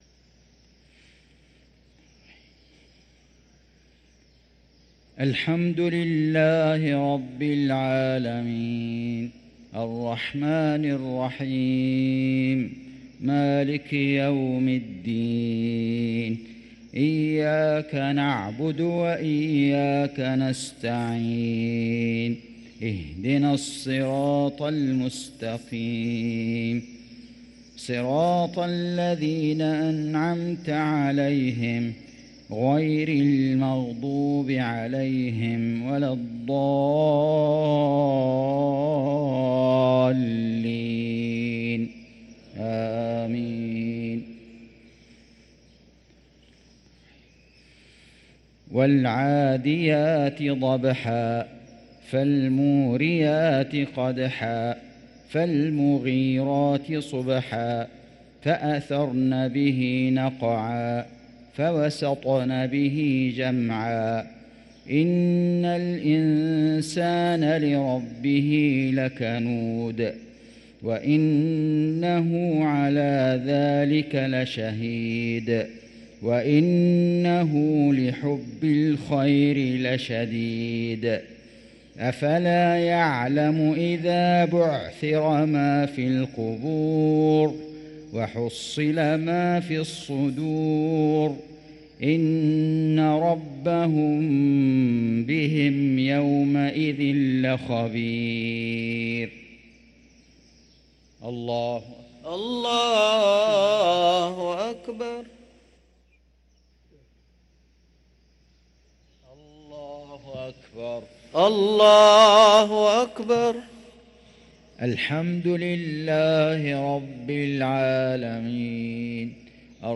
صلاة المغرب للقارئ فيصل غزاوي 6 جمادي الآخر 1445 هـ
تِلَاوَات الْحَرَمَيْن .